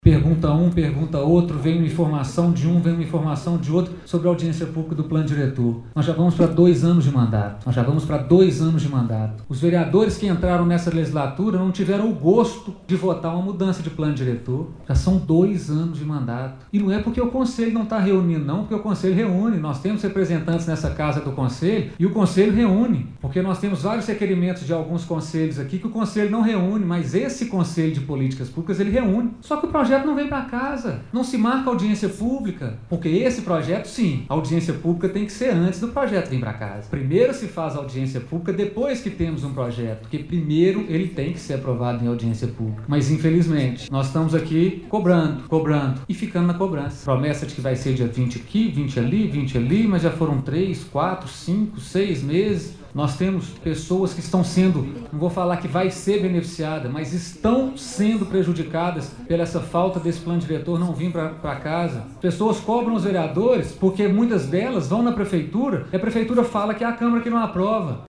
Esta foi a cobrança feita por Marcus Vinícius Rios Faria, presidente da Câmara Municipal. Durante pronunciamento na tribuna livre ele também ressaltou que o Conselho Municipal de Políticas Urbanas se reúne periodicamente para avaliar as alterações: